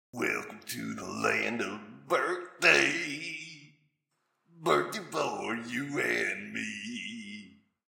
birth2.ogg